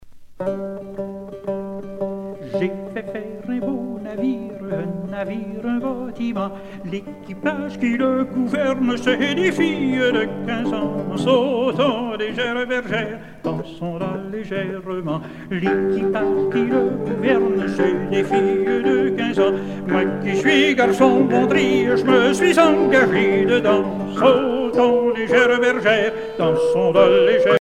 Usage d'après l'analyste circonstance : maritimes
Genre laisse